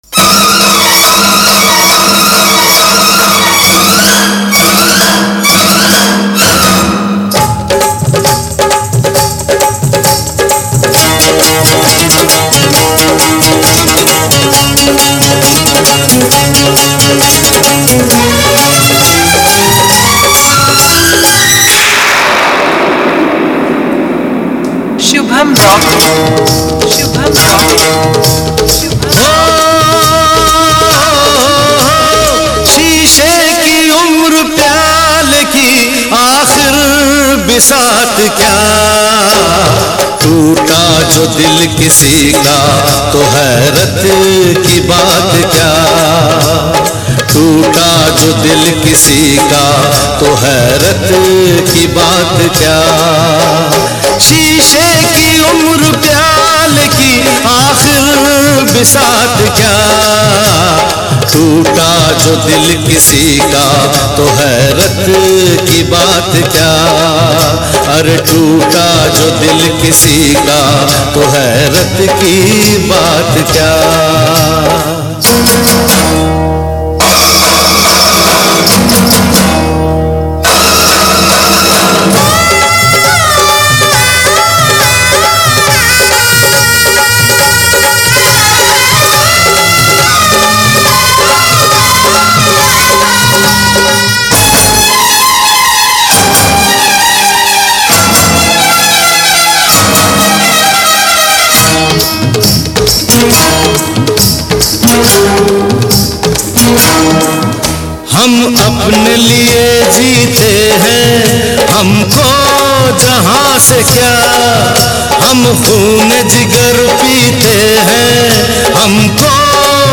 Mela Competition Filters Song